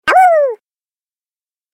دانلود آهنگ گرگ 6 از افکت صوتی انسان و موجودات زنده
دانلود صدای گرگ 6 از ساعد نیوز با لینک مستقیم و کیفیت بالا
جلوه های صوتی